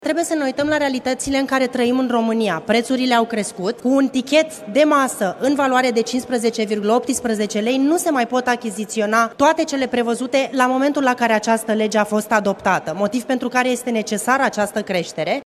Deputata PNL – Daniela Calista a spus în plenul camerei că acest proiect vine în ajutorul mediului privat.